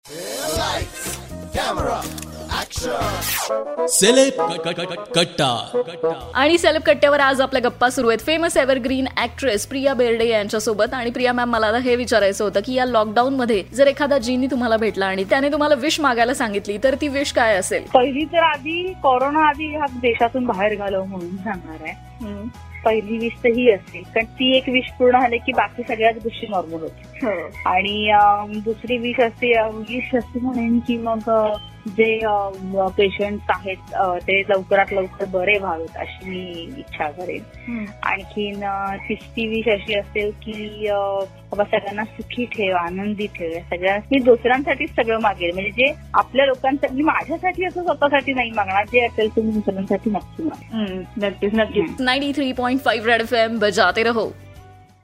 interview of famous actress Priya Berde..In this interview she shared her one and only wish for now..